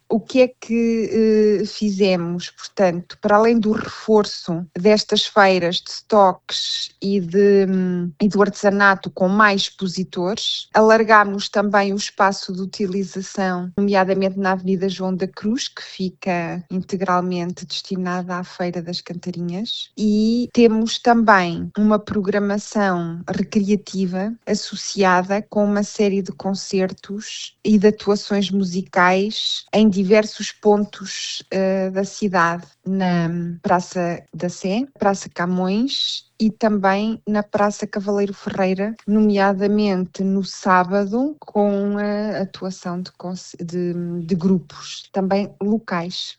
A presidente da Câmara Municipal de Bragança, Isabel Ferreira, sublinha que, além da conjugação de várias iniciativas, houve um aumento do número de expositores e uma programação mais intensa para dinamizar o centro histórico da cidade: